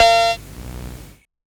SOUTHSIDE_percussion_amped_clav.wav